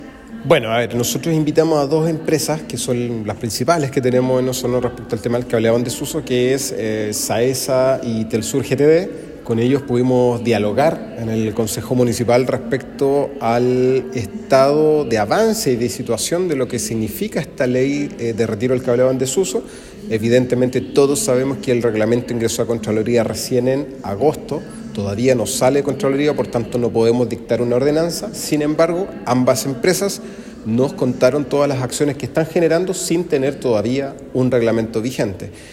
Por su parte, el presidente del Concejo Municipal de Osorno, Miguel Arredondo, señaló que esta reunión se centró en evaluar el estado de avance en el retiro de los cables en desuso, que se está dando por parte de las empresas en tanto se establece el nuevo reglamento vigente.